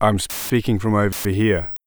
fuzzyspeech.wav